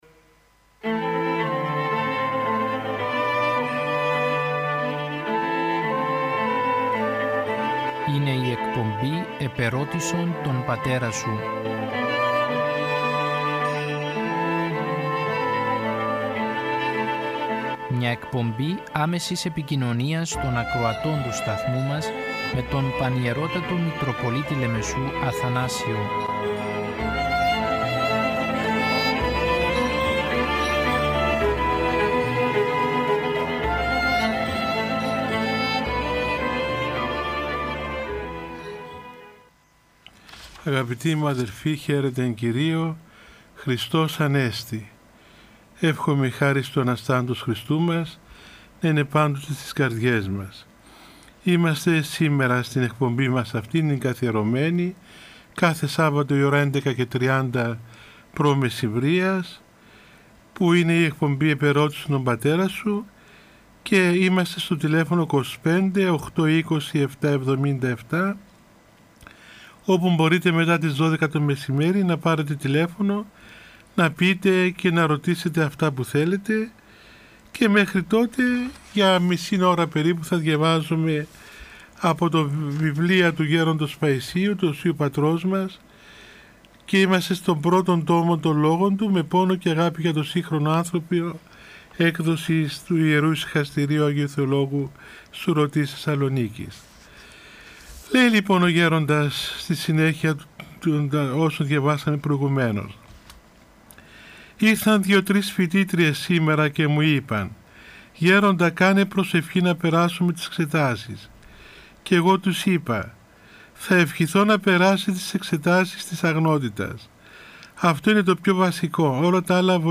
Το πρακτορείο Ορθοδοξία αναμεταδίδει την εκπομπή του Θεοφιλεστάτου Επισκόπου Αμαθούντος, κ. Νικολάου, που ασχολείται με θέματα Πίστεως και Ζωής της Ορθοδόξου Εκκλησίας μας. Η εκπομπή μεταδίδεται από τον Ραδιοφωνικό Σταθμό της Ιεράς Μητροπόλεως Λεμεσού.